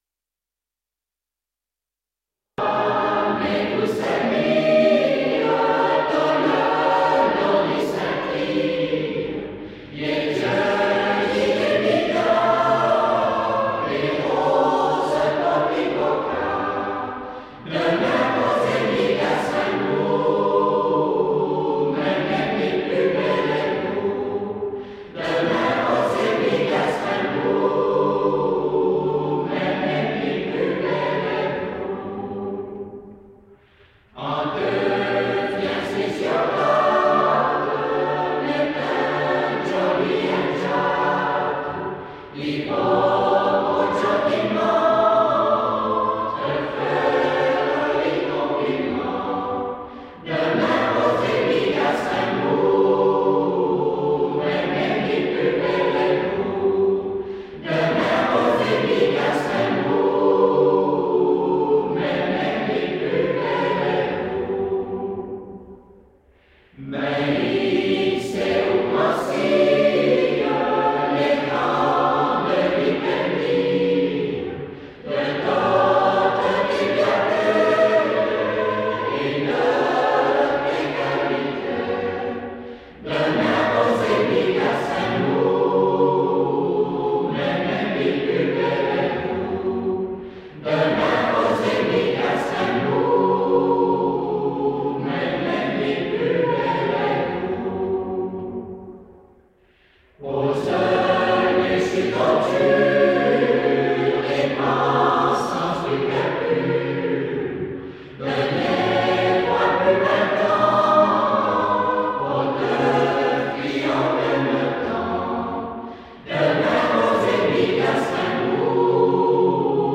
Version chantée par la chorale de l’Amicale des Patoisants Vadais